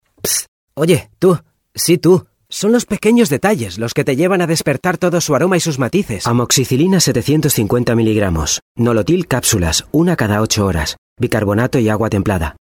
Excelente dicción, vocalización y sonido brillante.
Utilizamos micrófonía Neuman U87 y Audio Technica 4050/CM5.
Sprechprobe: Industrie (Muttersprache):